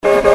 Steam